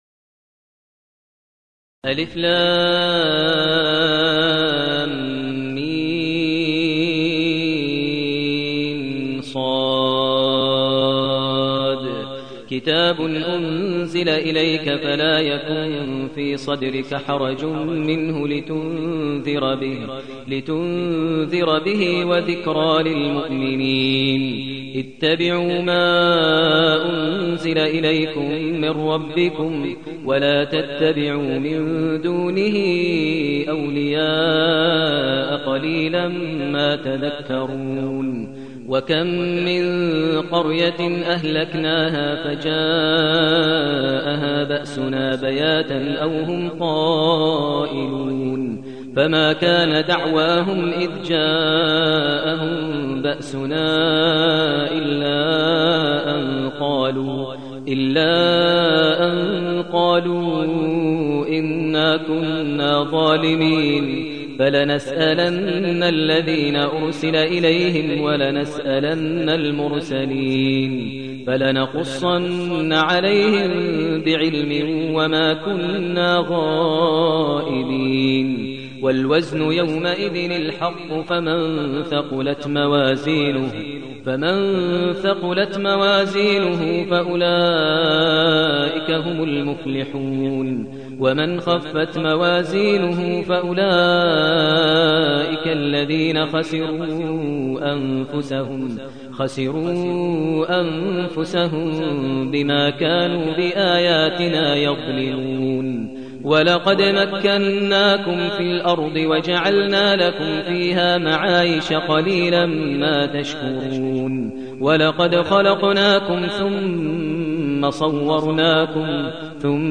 المكان: المسجد النبوي الشيخ: فضيلة الشيخ ماهر المعيقلي فضيلة الشيخ ماهر المعيقلي الأعراف